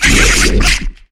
ryuchiChitter2.wav